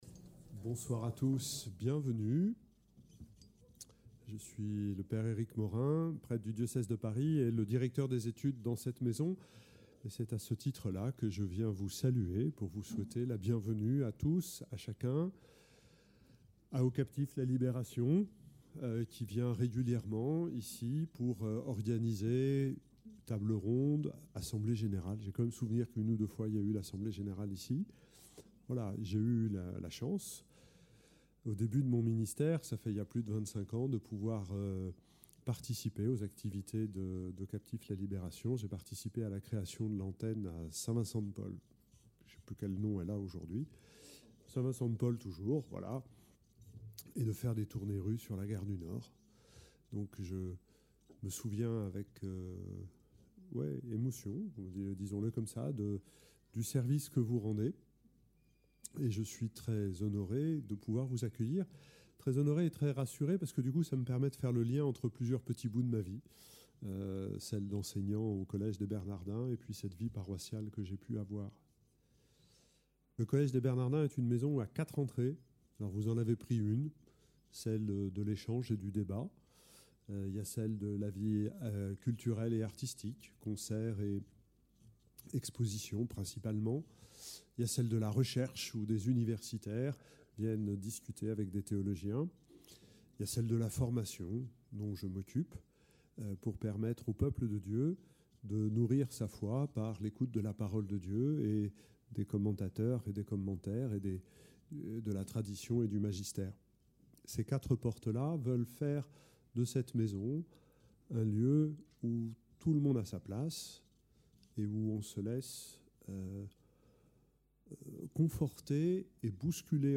Témoignage
Entre témoignage et expertises, les invités ont pu nous donner des clés de compréhension sur un véritable sujet de société, certes complexe mais dont chacun peut se saisir et agir à son échelle.